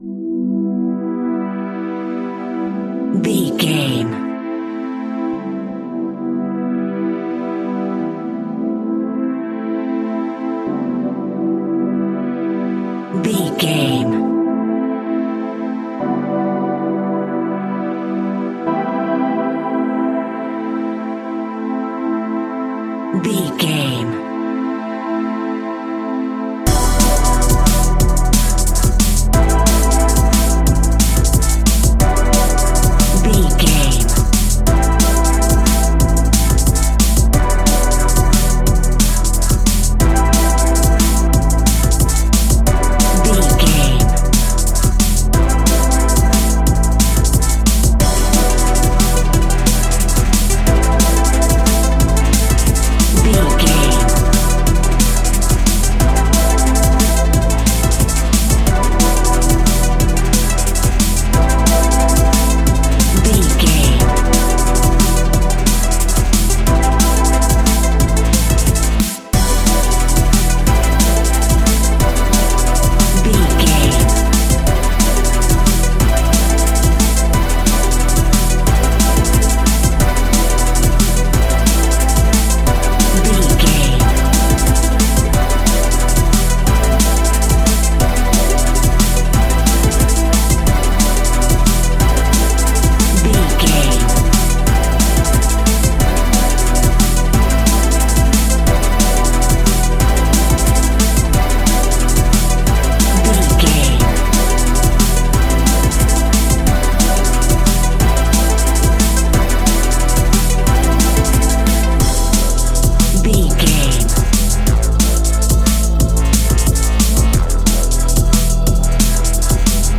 Epic / Action
Fast paced
Aeolian/Minor
aggressive
dark
driving
energetic
futuristic
synthesiser
drum machine
electronic
sub bass
synth leads